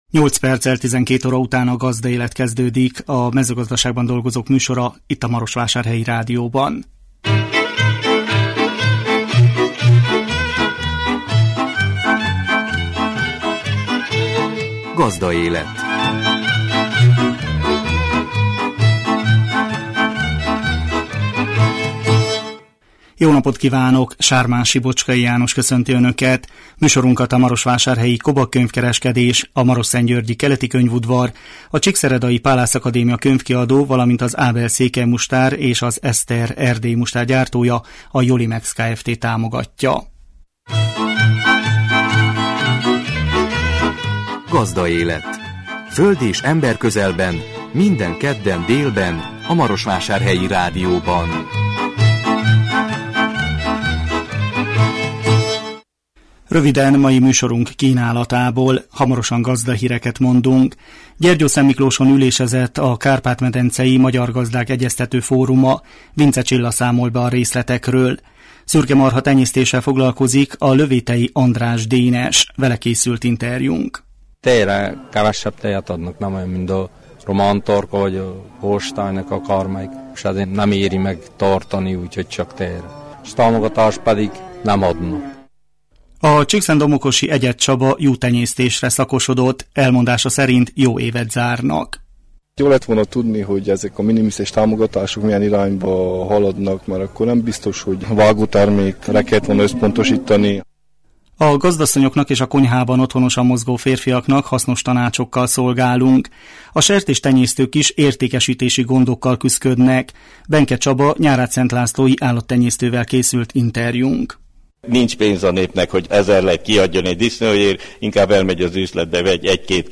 Vele készült interjúnk.